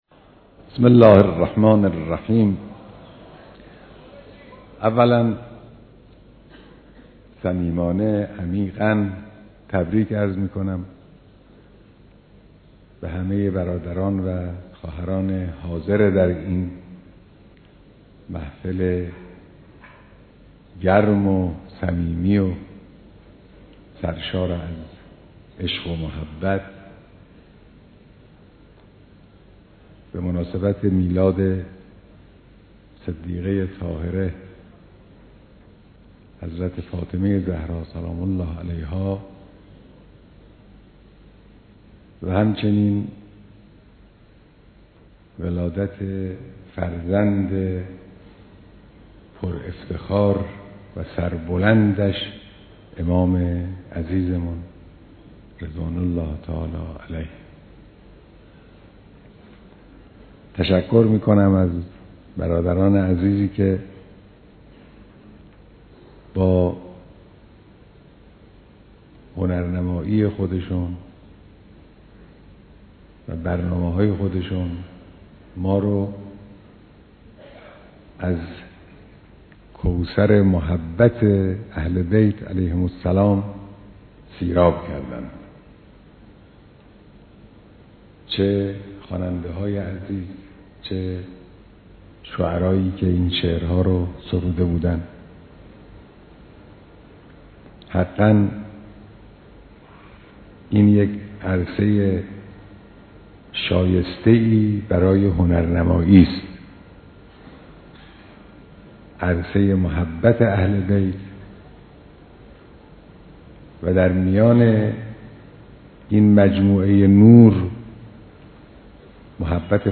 مراسم مولودی خوانی در خجسته سالروز میلاد حضرت فاطمه (س) در حضور رهبر معظم انقلاب